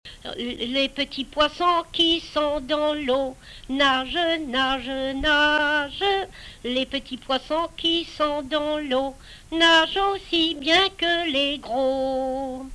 Chanson Item Type Metadata
Emplacement Miquelon